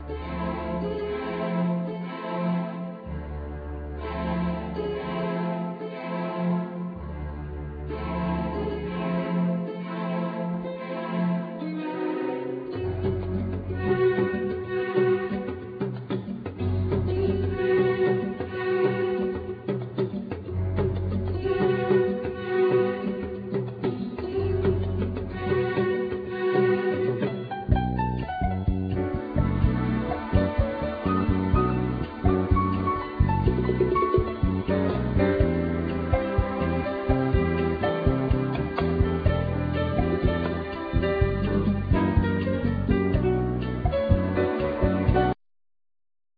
Guitar,Harmonica,Programing,Guitar Synthe
Drums
Bandneon
Vocal
Bass
Keyboards
Piano
DoubleBass
Percussions